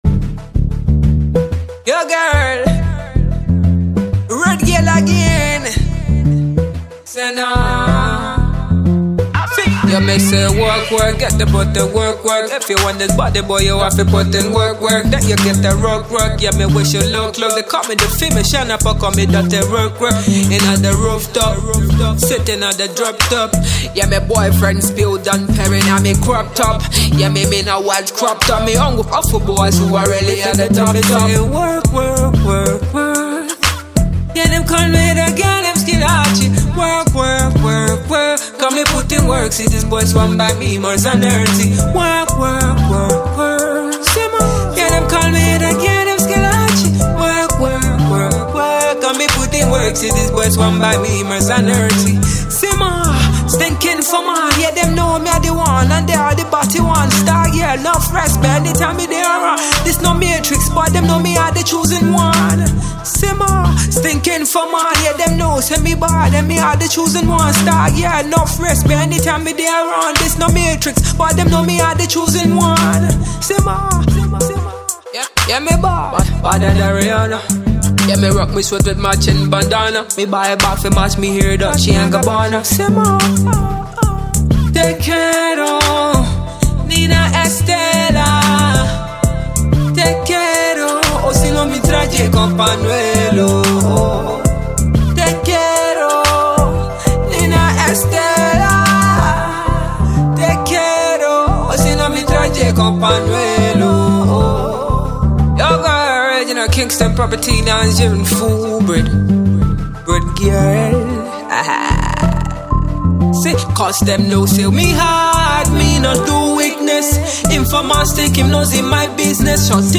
bootleg cover